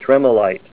Say TREMOLITE Help on Synonym: Synonym: ICSD 30126   PDF 44-1402